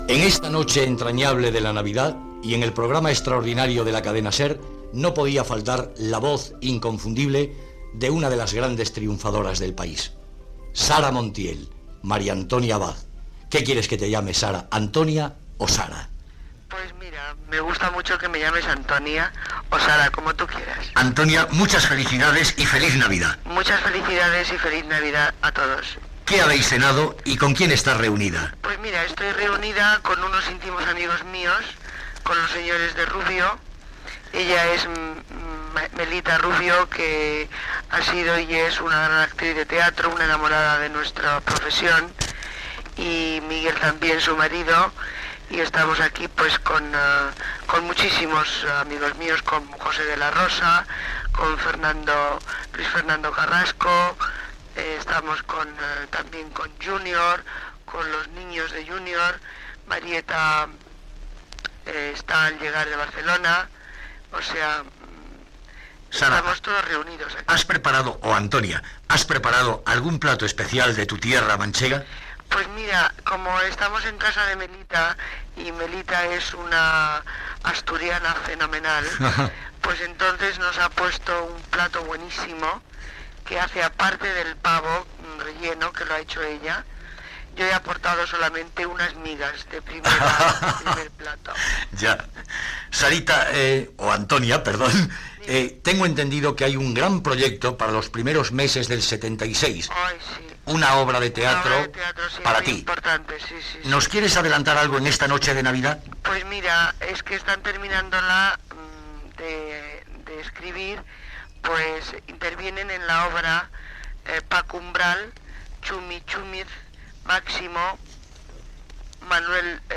Entrevista a l'actriu Sara Montiel (Antonia Abad) sobre com està passant la nit de Nadal i l'obra de teatre que interpretarà l'any 1977.